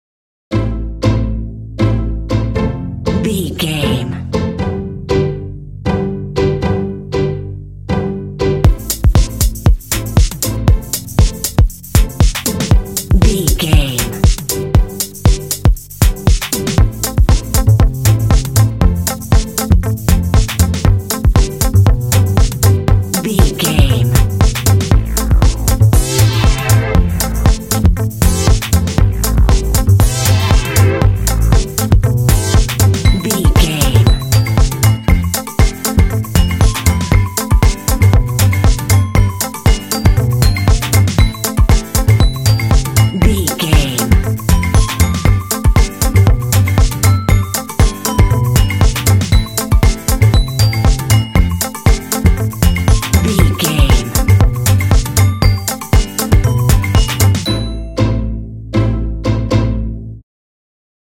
This cute track is ideal for underscoring kids games!
Aeolian/Minor
groovy
cool
strings
drums
percussion
synthesiser
contemporary underscore